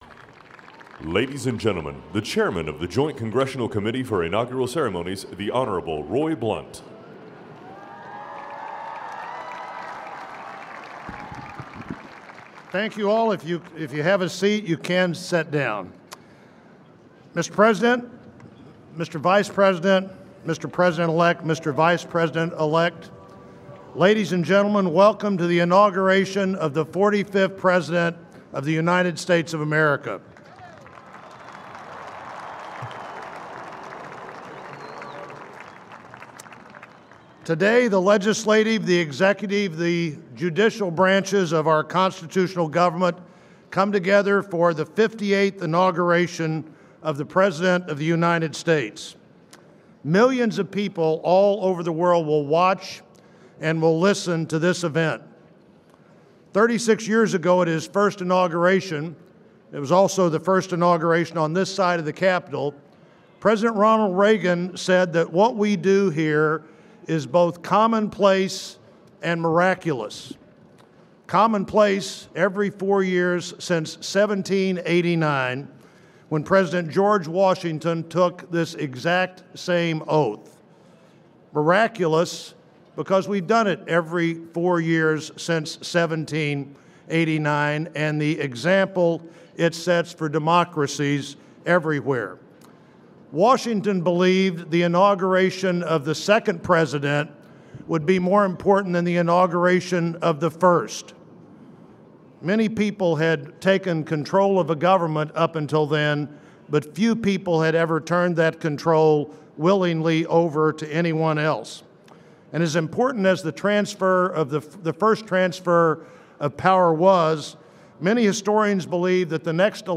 January 20, 2017: Inaugural Address
Presidential Speeches | Donald Trump Presidency January 20, 2017: Inaugural Address